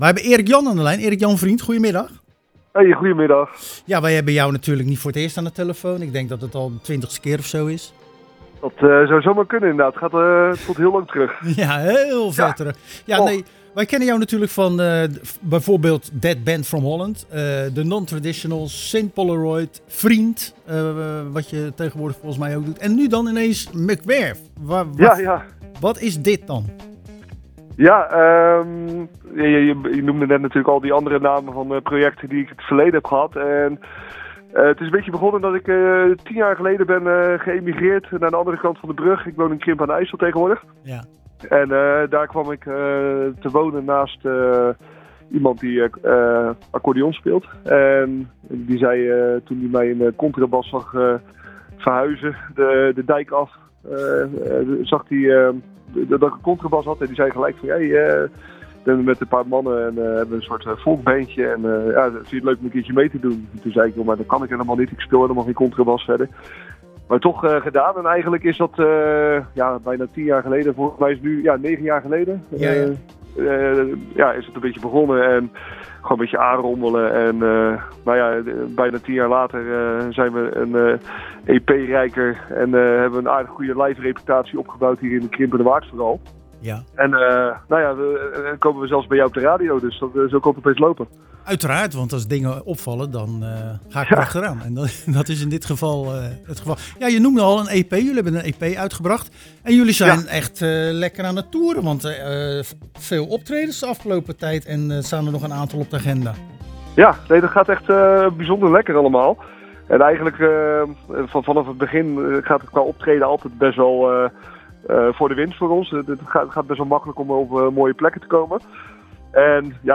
Tijdens de wekelijkse editie van het programma Zwaardvis belden we naar de overkant van de Algerabrug met